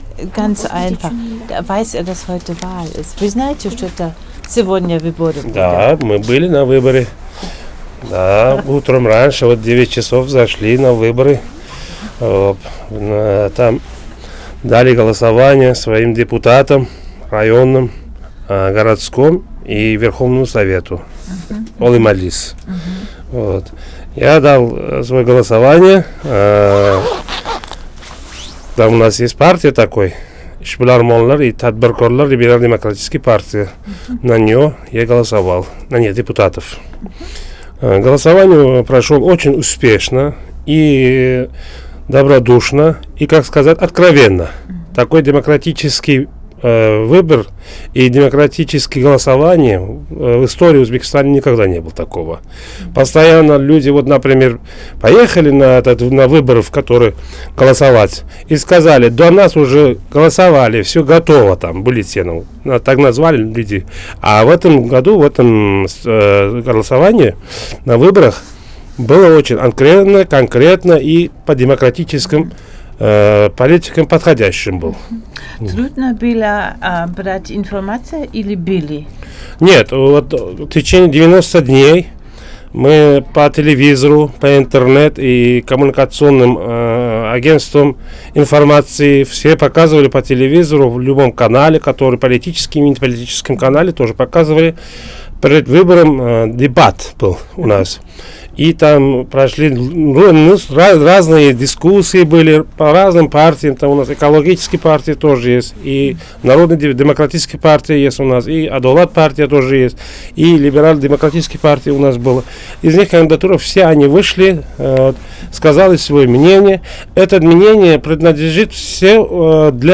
Ein Händler auf dem Basar in Taschkent berichtet: